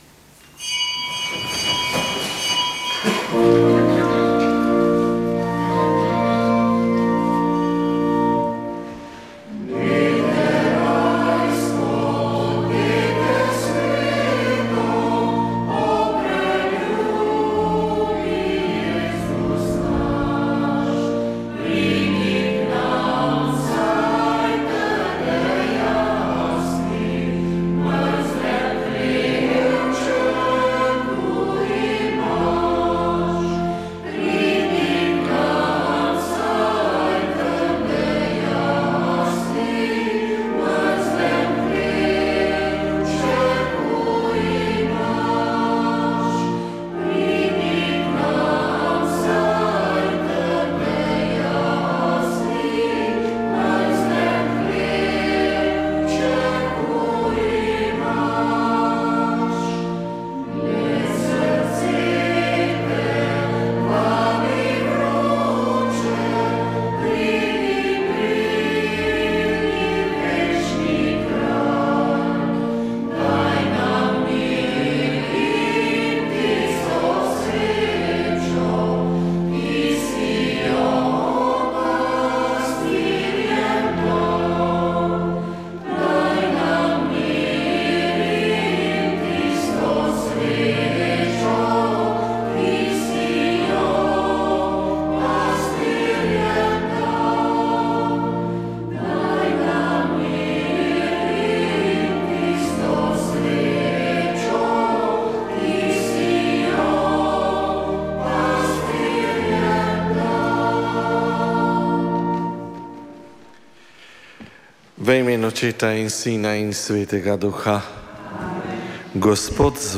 Sveta maša
Sveta maša iz kapele Radia Ognjišče